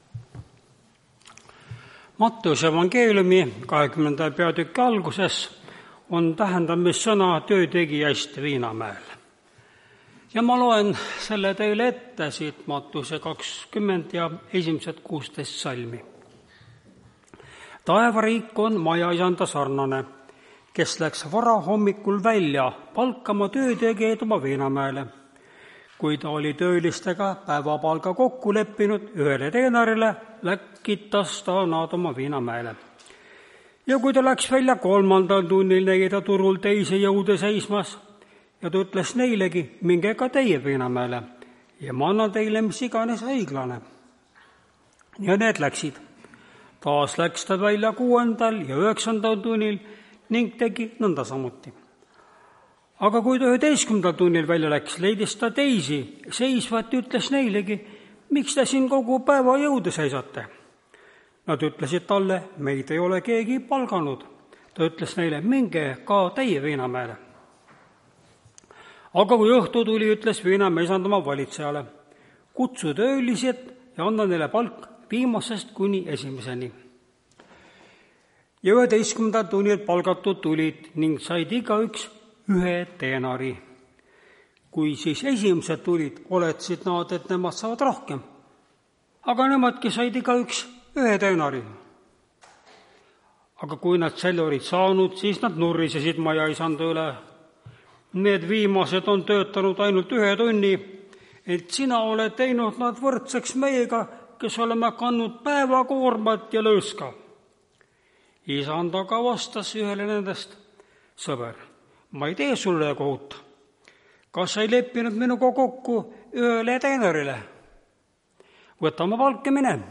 Tartu adventkoguduse 15.03.2025 hommikuse teenistuse jutluse helisalvestis.